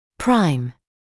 [praɪm][прайм]первостепенный; первоочередной